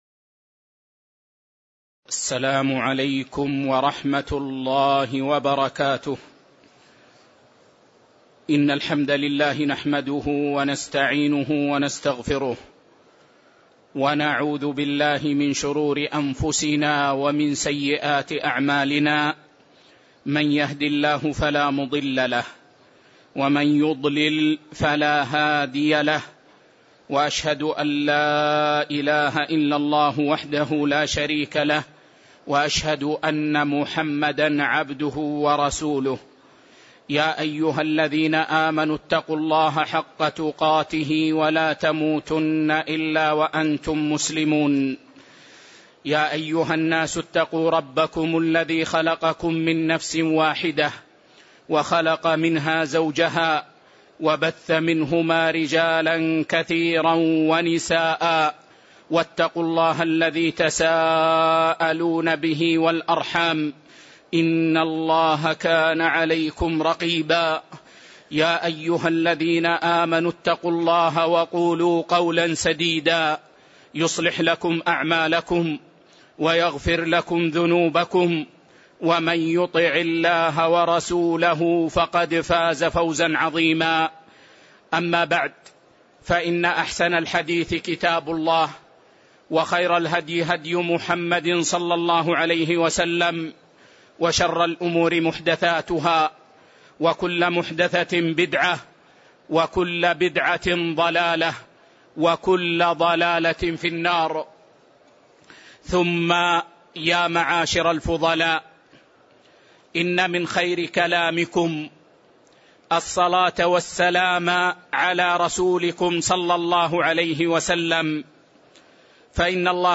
تاريخ النشر ٢٤ جمادى الأولى ١٤٣٧ هـ المكان: المسجد النبوي الشيخ